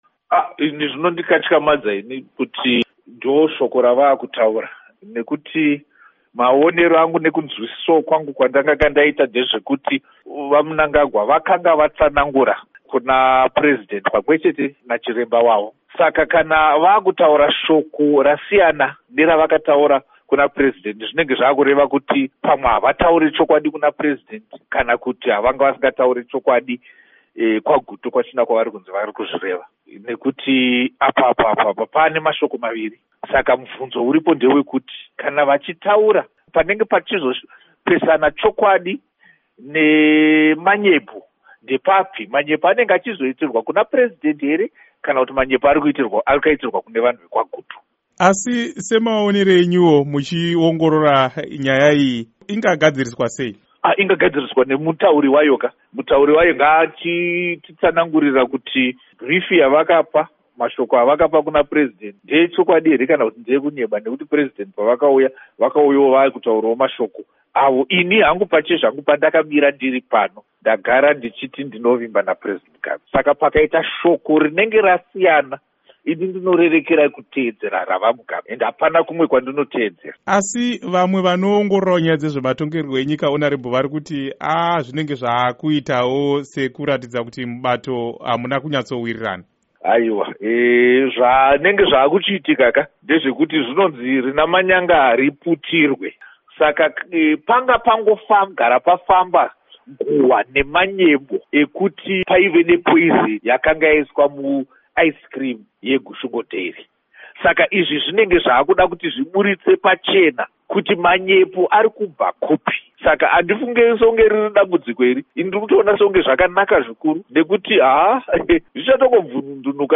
Hurukuro naVaPatrick Zhuwao